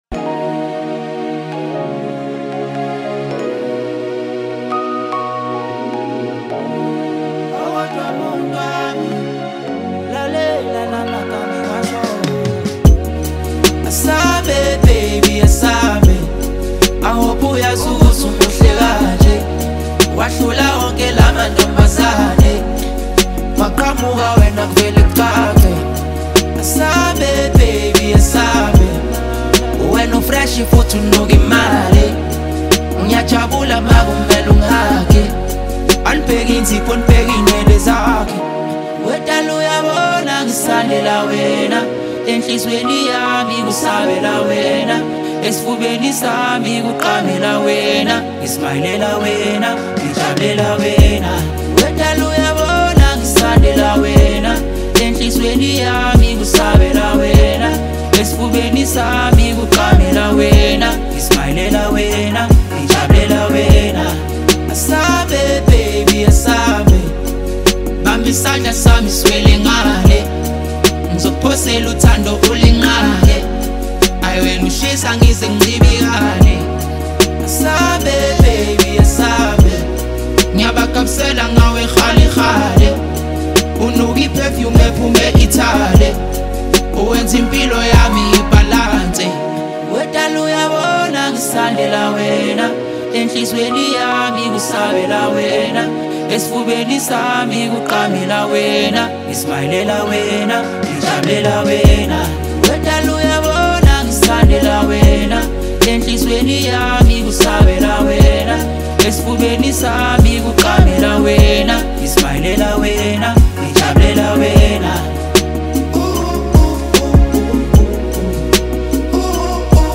Award-winning South African music duo